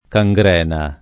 cangrena [ ka jg r $ na ]